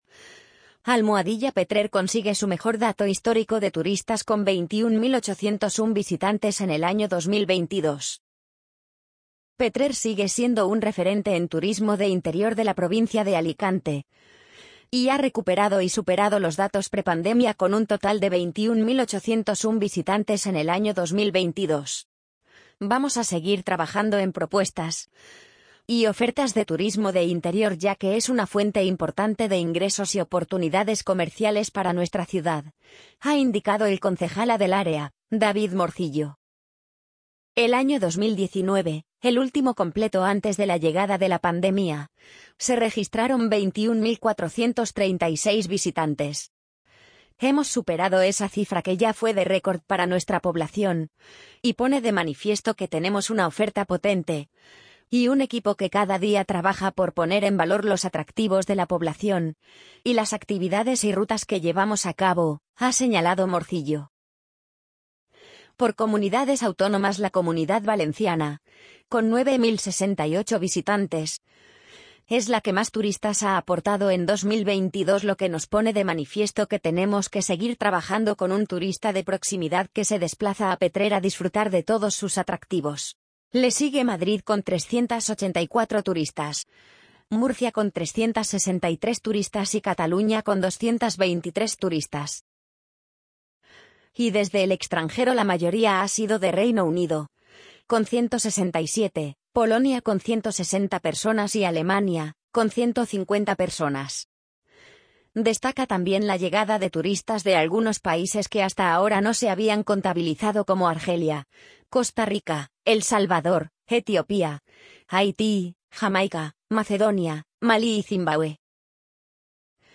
amazon_polly_63099.mp3